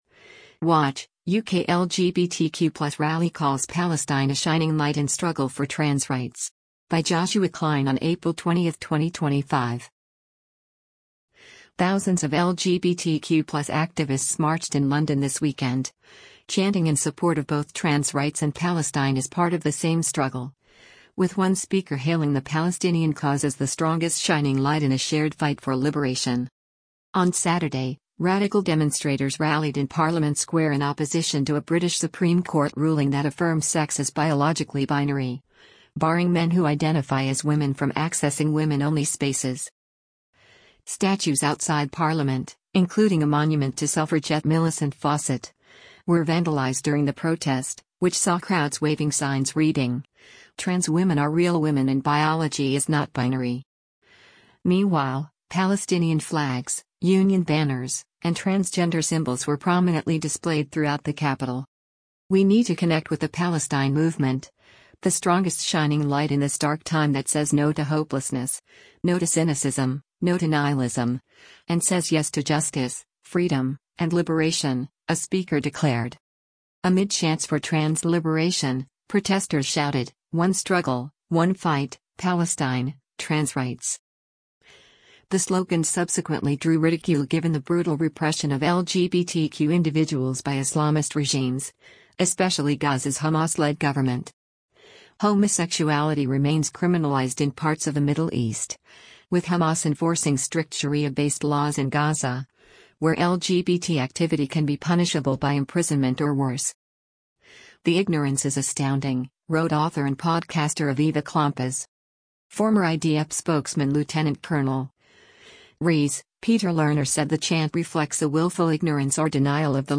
Thousands of LGBTQ+ activists marched in London this weekend, chanting in support of both “trans rights” and “Palestine” as part of the same struggle — with one speaker hailing the Palestinian cause as “the strongest shining light” in a shared fight for “liberation.”
Amid chants for “trans liberation,” protesters shouted: “One struggle, one fight: Palestine — trans rights.”